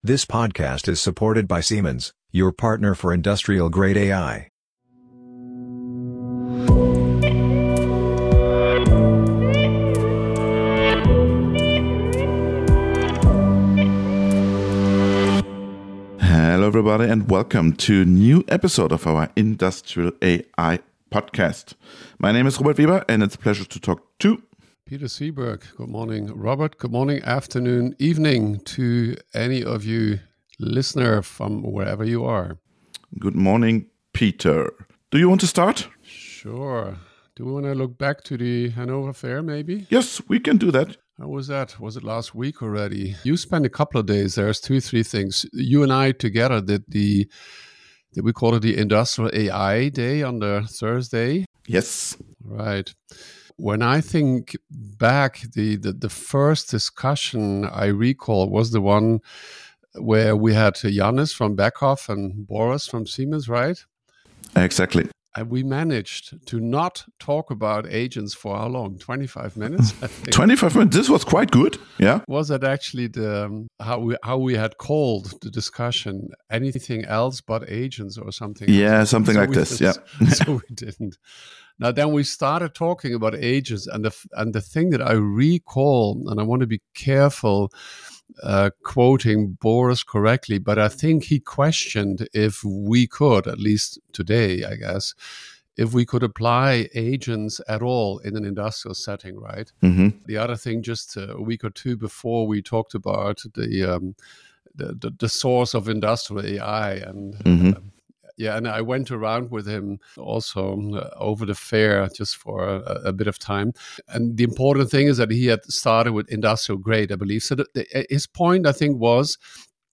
The episode was recorded on the fringes of the Beyond Safety Forum at the beginning of December. Safety experts and AI thought leaders discussed the interplay between the two disciplines on site.